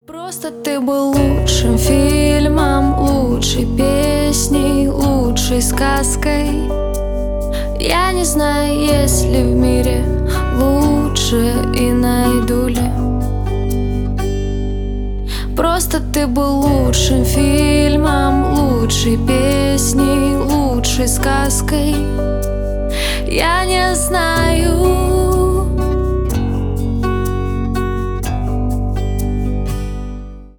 Поп Музыка
грустные